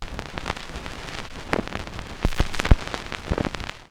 Record Noises
Record_End_Long Old.aif